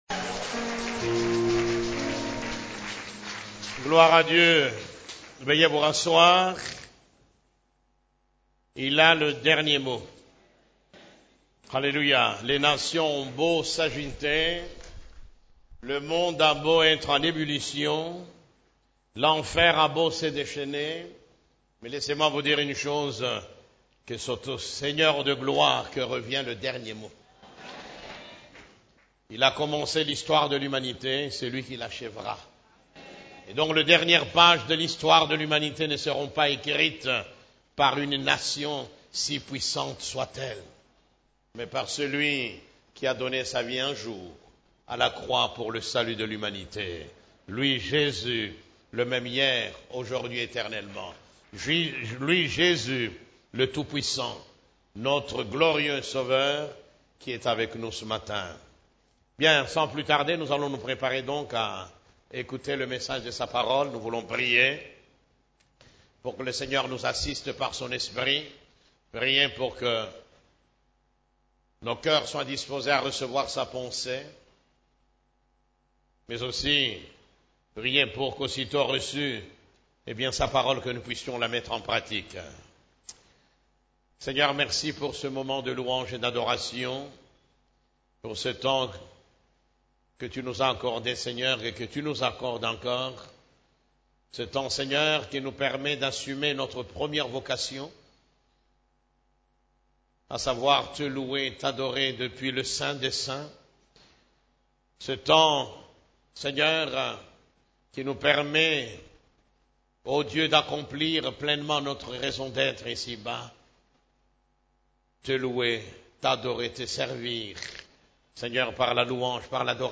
CEF la Borne, Culte du Dimanche, Comment voir l'invisible ?